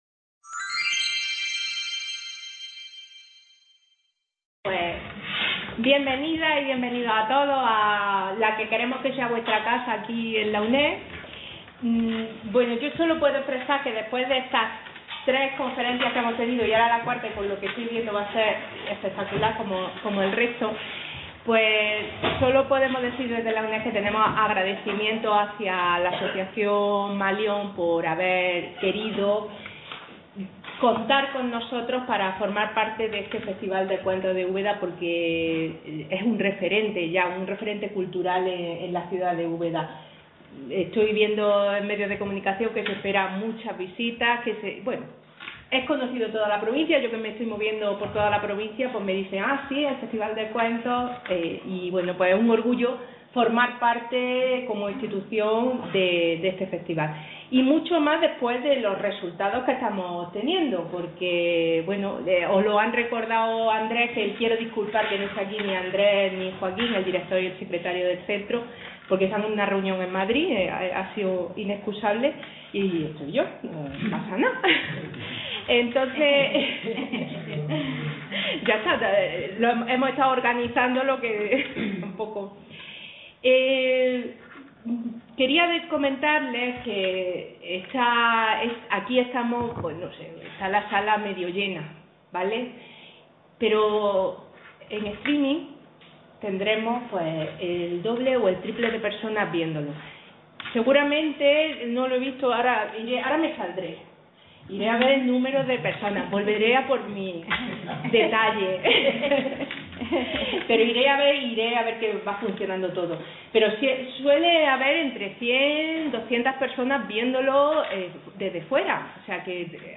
(Romances contados y cantados)